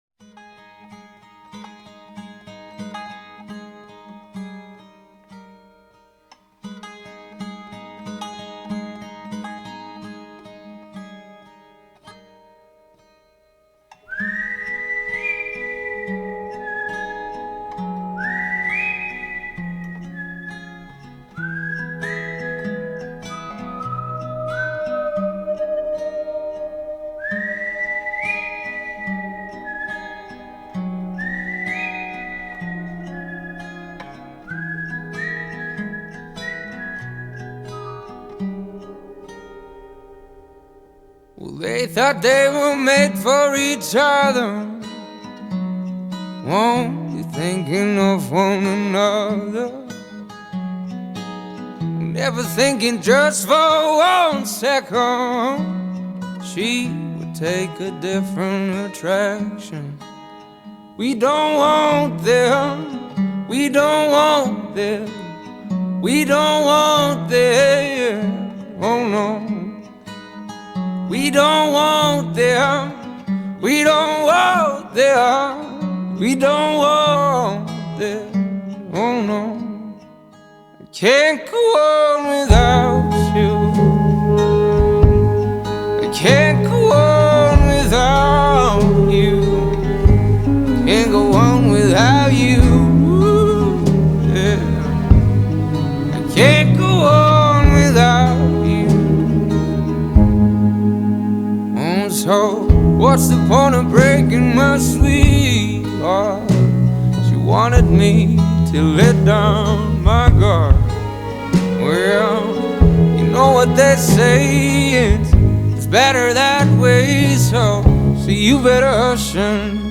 آهنگ خیلی غم انگیزیه
آلترناتیو راک